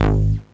cch_bass_one_shot_bright_G#.wav